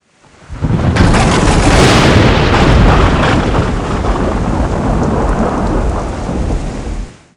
thunder.wav